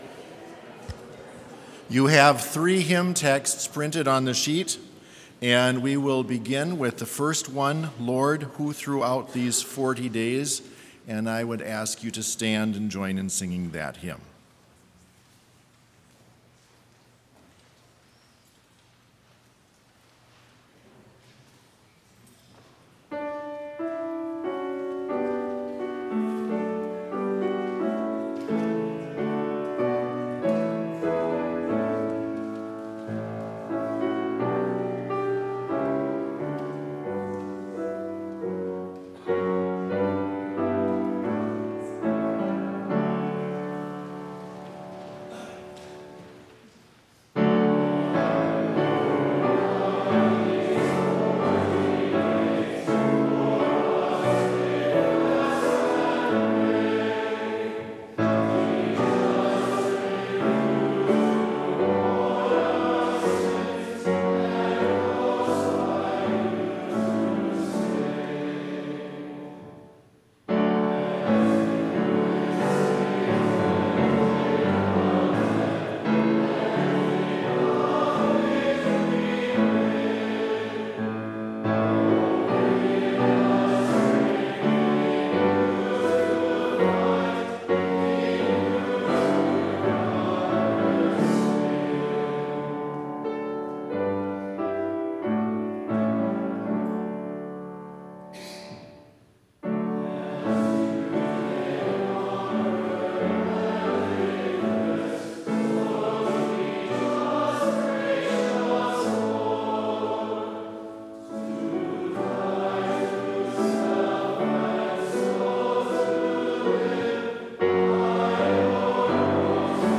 Complete service audio for Chapel - February 27, 2020